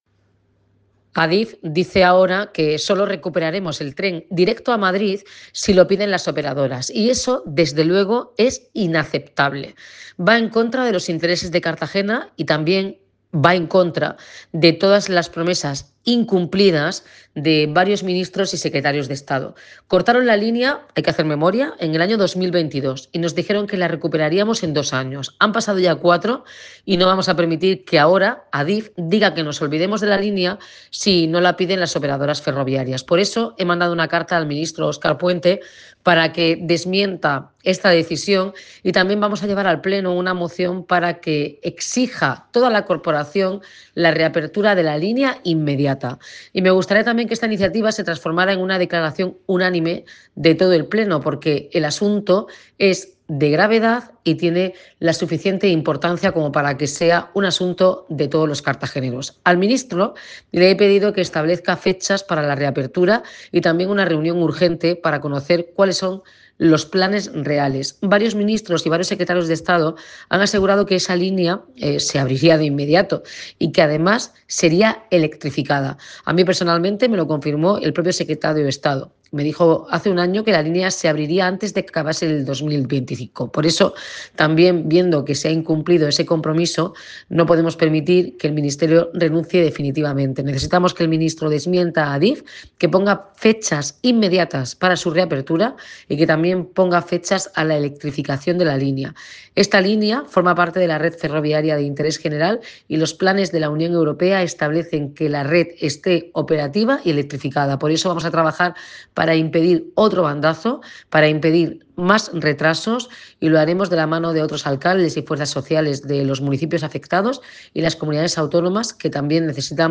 Enlace a Declaraciones de la alcaldesa Noelia Arroyo.